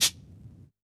Index of /musicradar/Kit 8 - Vinyl
CYCdh_VinylK1-Shkr03.wav